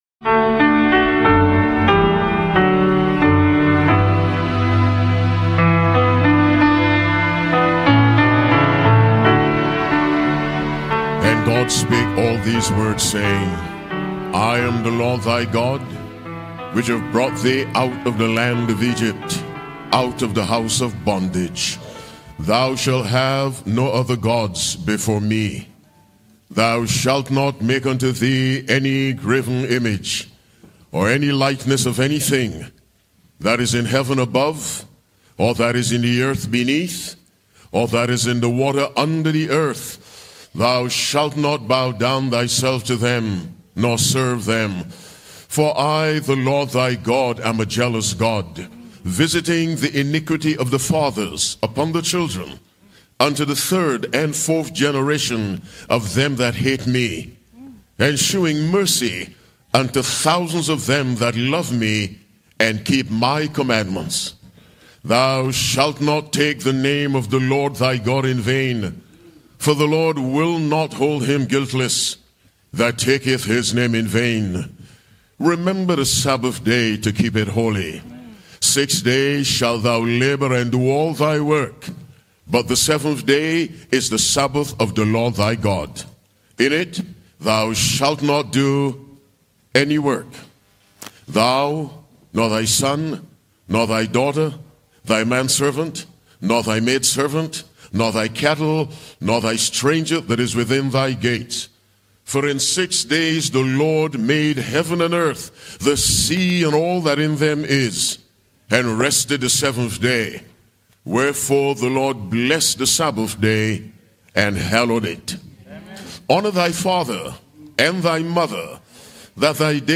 Discover the transformative power of faith in this compelling sermon exploring the Ten Commandments, monotheism, forgiveness, and surrendering to Christ. From the significance of baptism to the urgency of spiritual decisions, this message calls you to deepen your relationship with God and embrace His authority in your life.